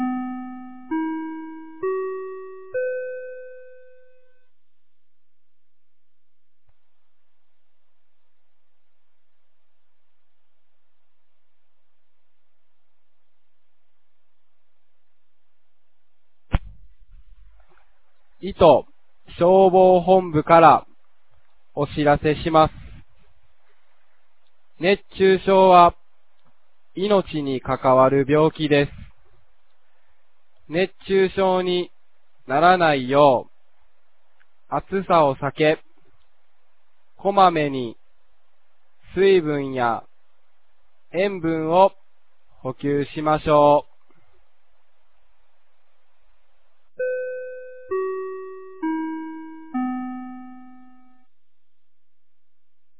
2024年08月05日 10時01分に、九度山町より全地区へ放送がありました。
放送音声